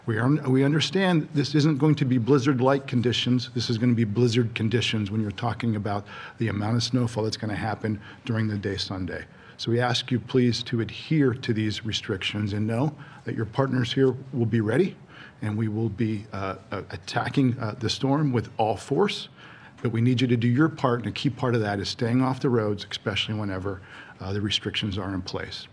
At a new conference in Harrisburg earlier this afternoon, Turnpike CEO Mark Compton reiterated how dangerous conditions could get, and how important it is for residents to adhere to any restrictions in place.